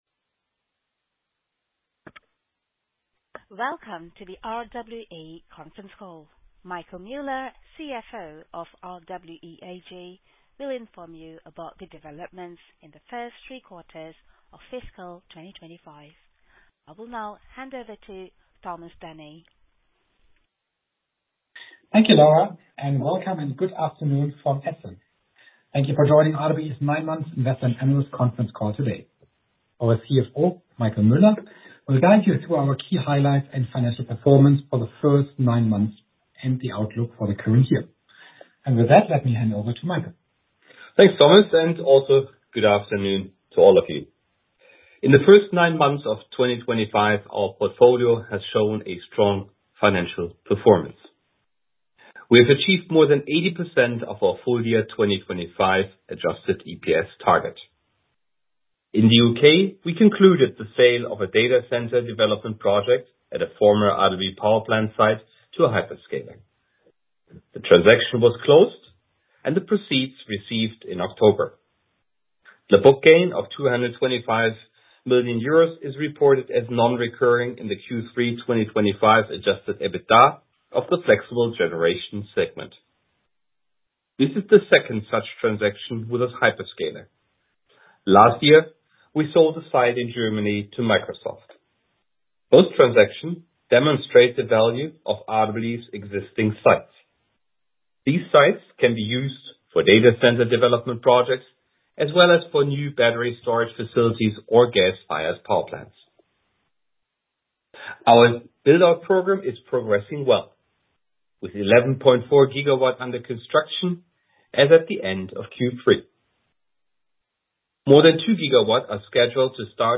Investor and analyst conference call